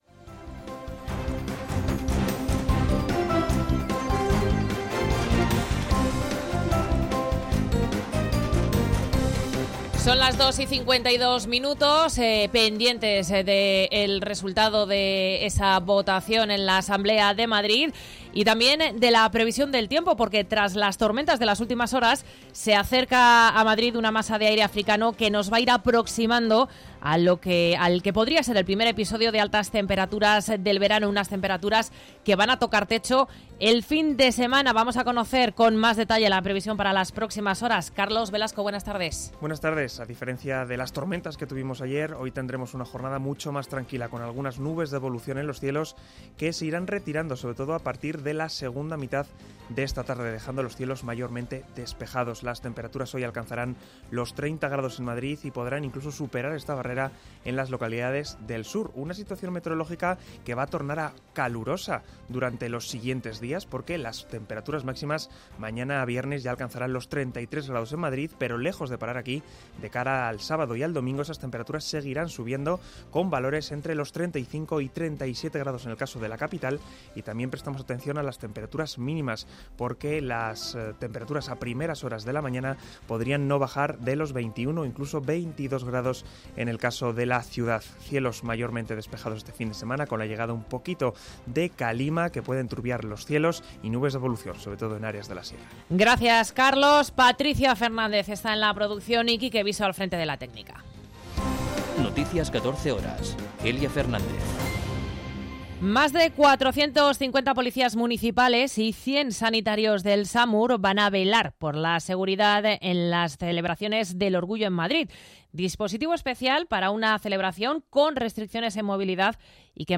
Noticias 14 horas 22.06.2023